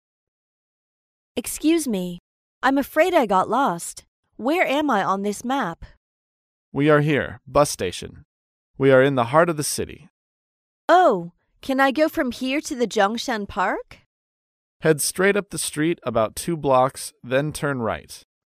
在线英语听力室高频英语口语对话 第21期:找宾馆的听力文件下载,《高频英语口语对话》栏目包含了日常生活中经常使用的英语情景对话，是学习英语口语，能够帮助英语爱好者在听英语对话的过程中，积累英语口语习语知识，提高英语听说水平，并通过栏目中的中英文字幕和音频MP3文件，提高英语语感。